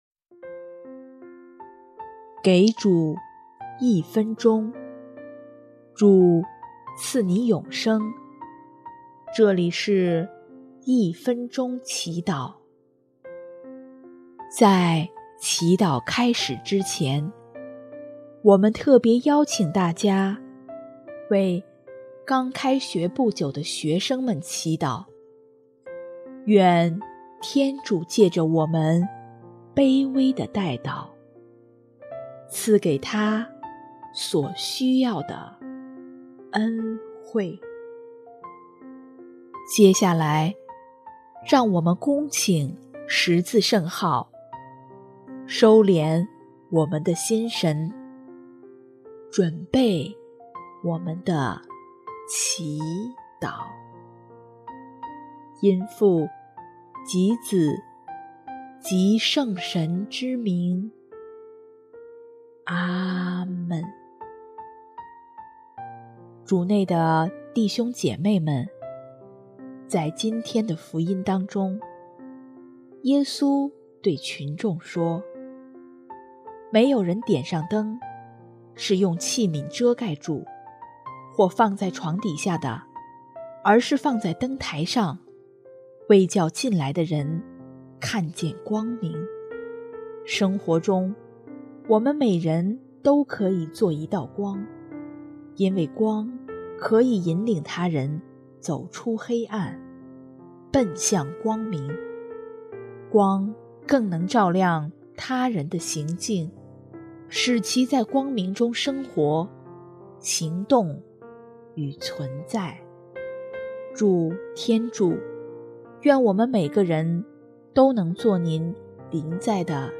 【一分钟祈祷】|9月25日 成为他人的光